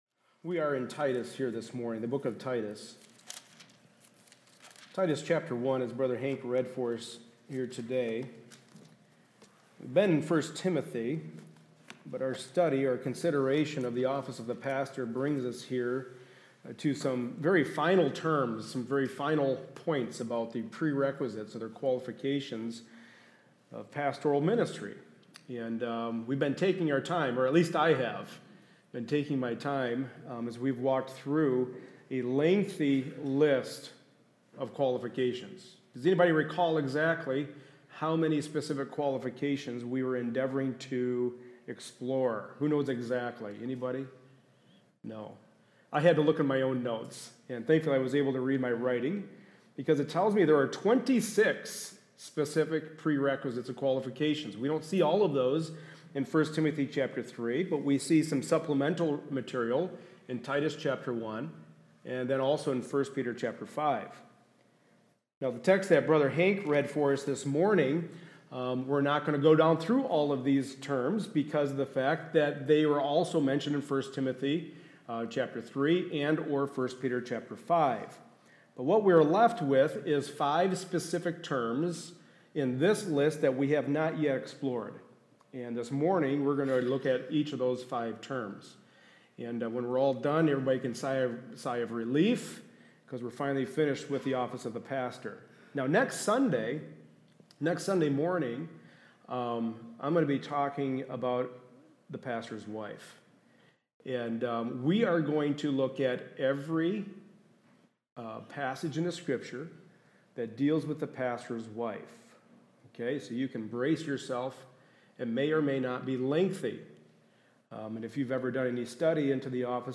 Titus 1 Service Type: Sunday Morning Service A study in the pastoral epistles.